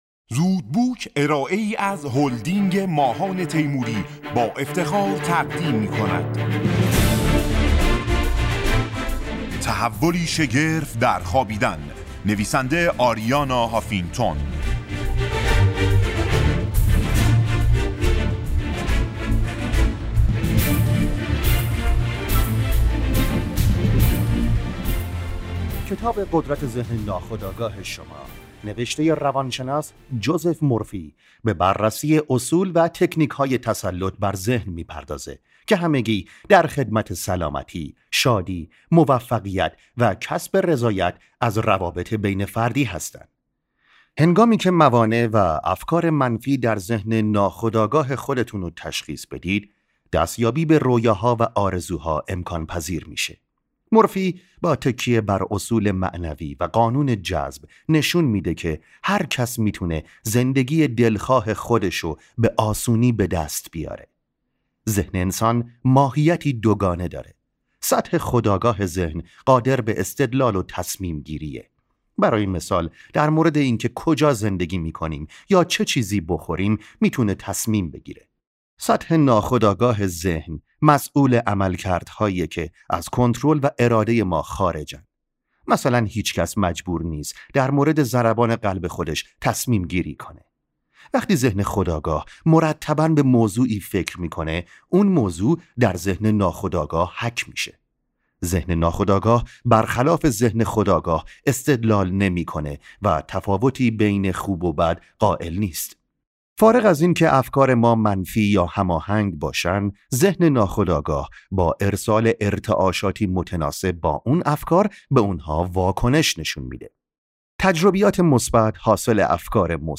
خلاصه کتاب صوتی قدرت ذهن ناخودآگاه